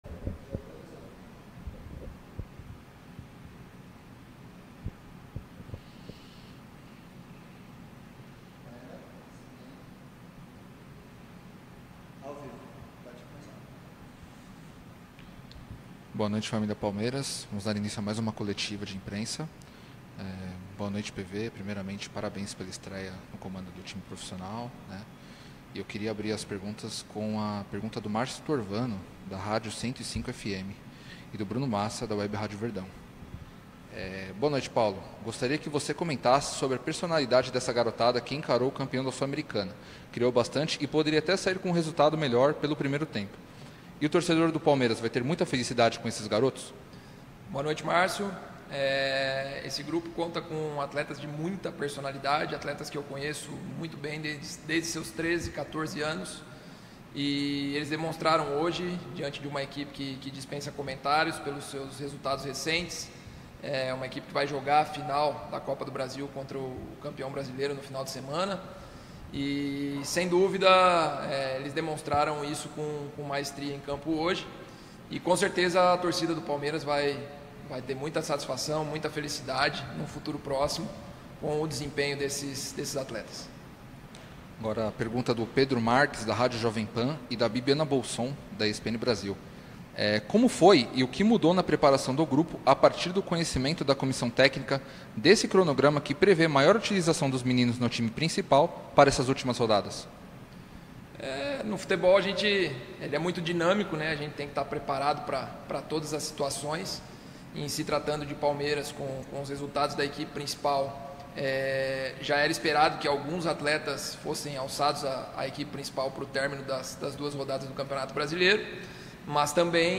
COLETIVA-_-ATHLETICO-X-PALMEIRAS-_-BRASILEIRO-2021.mp3